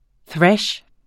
Udtale [ ˈθɹaɕ ]